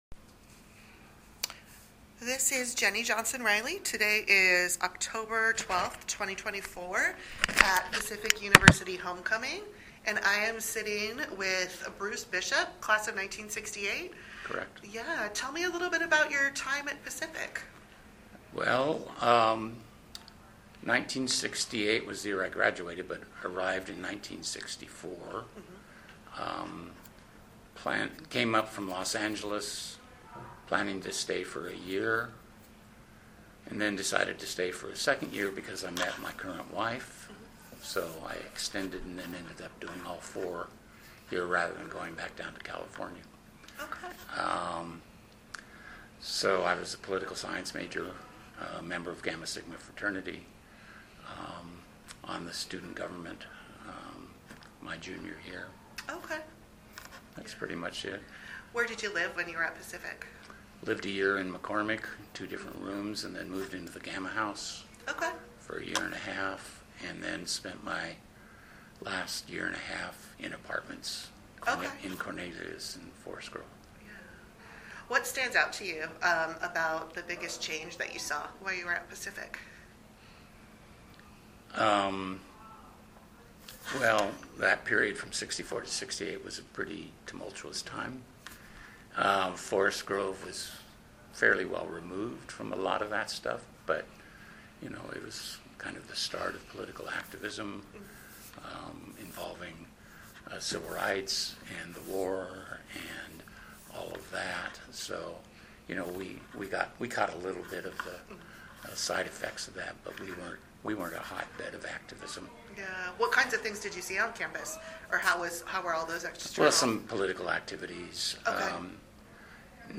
This is one of a group of recordings made during a reunion in October, 2024.